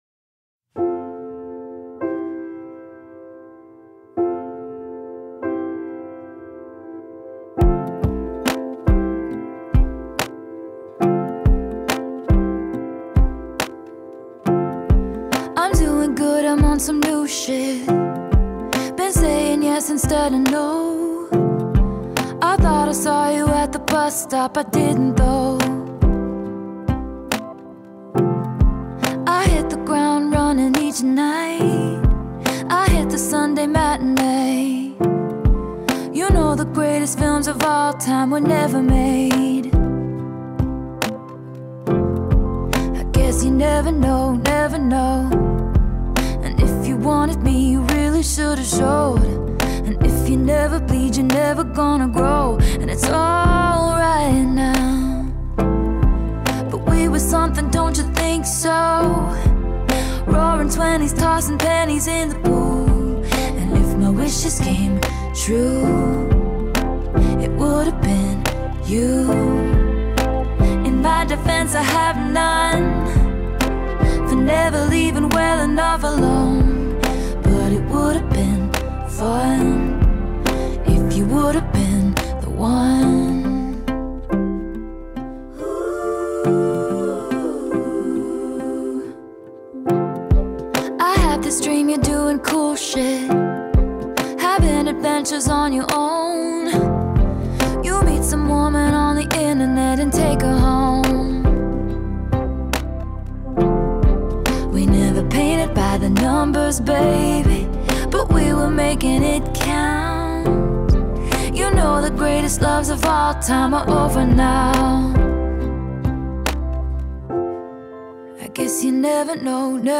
Alternative Folk, Indie Pop